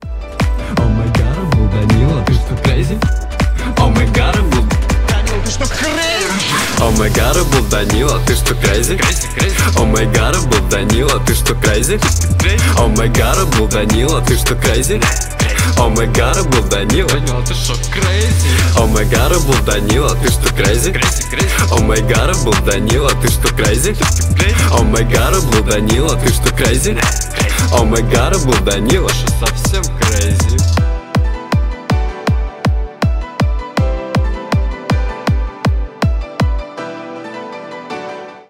• Качество: 128, Stereo
поп
ритмичные
веселые
клавишные
четкие